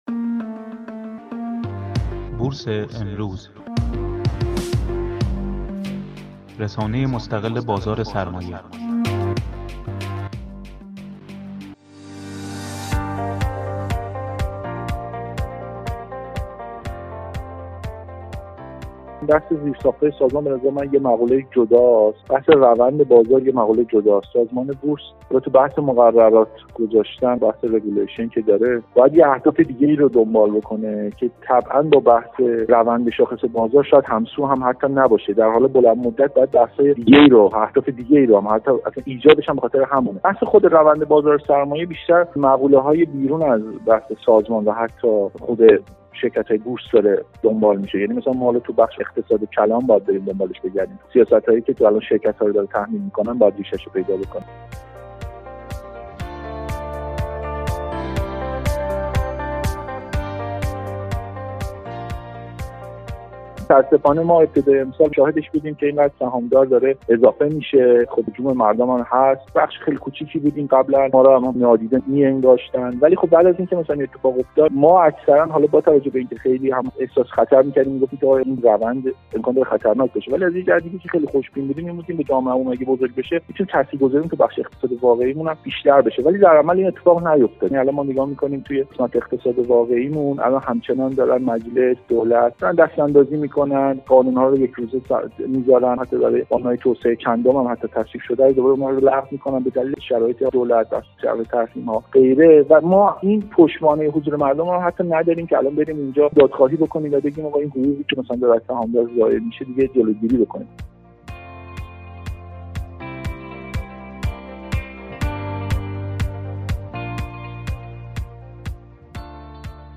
یک کارشناس بازار سرمایه در انتقاد به عملکرد سازمان بورس؛ بازار سرمایه به محلی برای آزمون و خطا تبدیل شده است